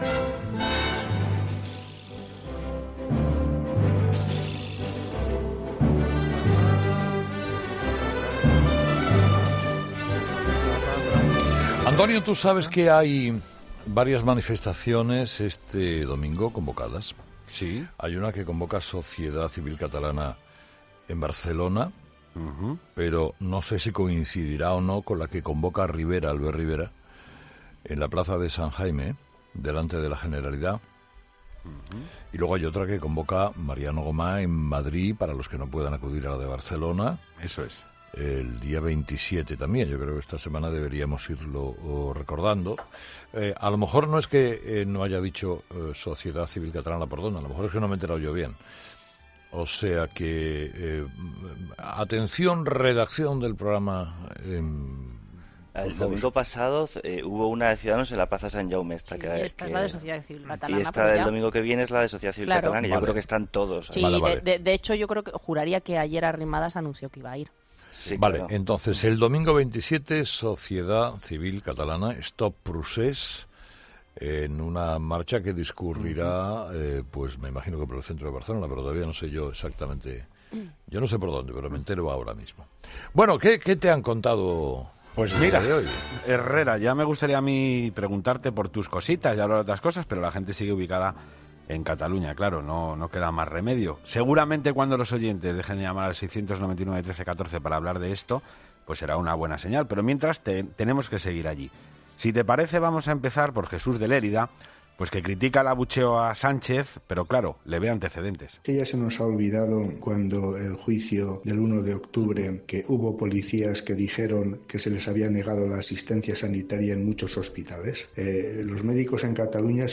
Avalancha de mensajes en el contestador de ‘Herrera en COPE’ , los oyentes continúan preocupados por la situación de Cataluña.